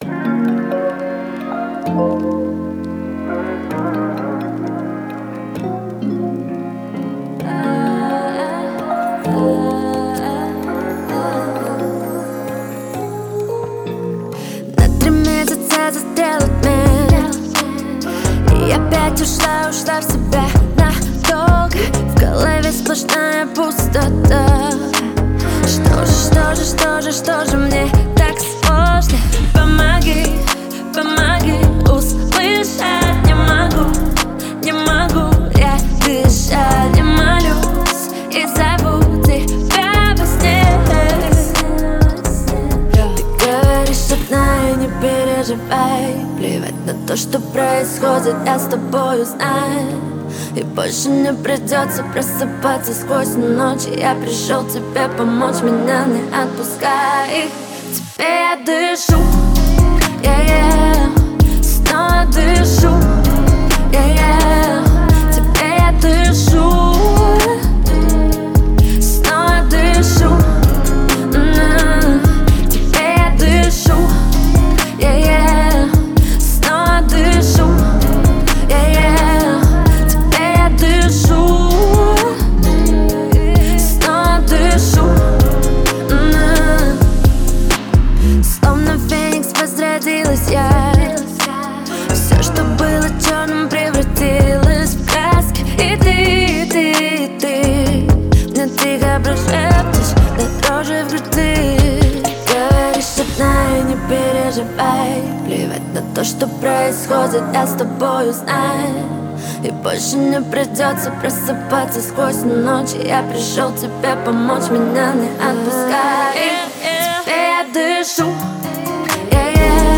это современное поп-балладное произведение